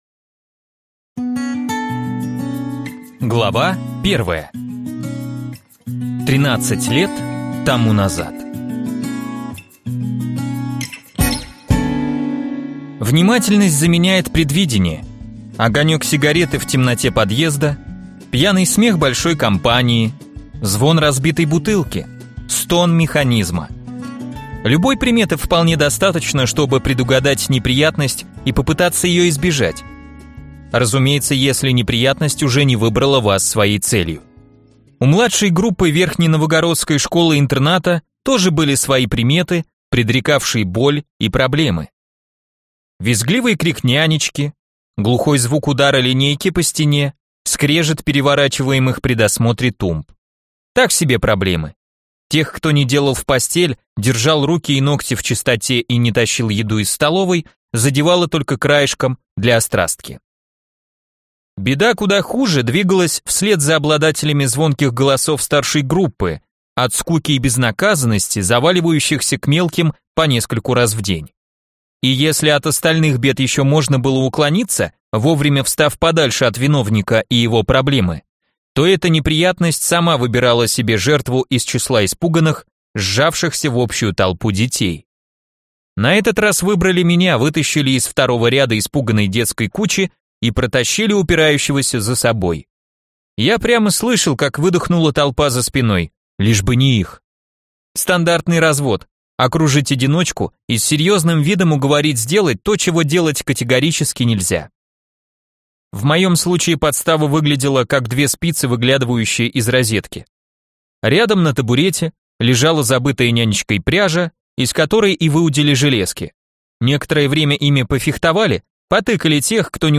Аудиокнига «Напряжение» в интернет-магазине КнигоПоиск ✅ в аудиоформате ✅ Скачать Напряжение в mp3 или слушать онлайн